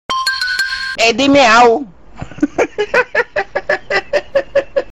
sonido miau
miau.mp3